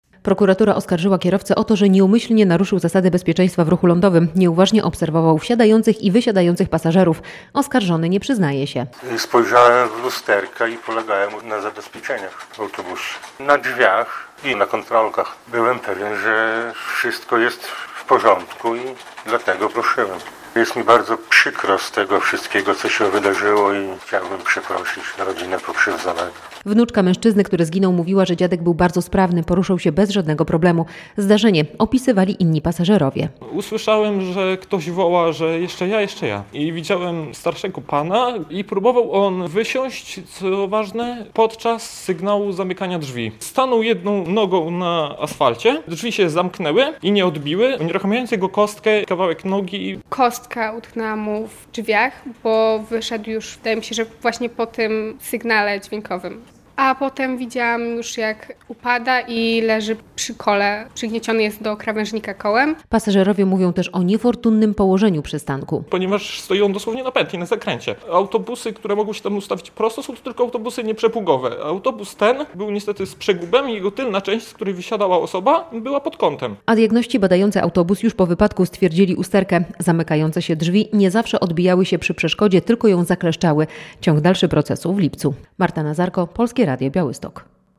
Proces kierowcy autobusu oskarżonego w sprawie śmiertelnego wypadku - relacja